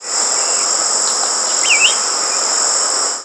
Summer Tanager diurnal flight calls
"Plew-wi" call from perched bird.
Perched birds giving a variety of calls.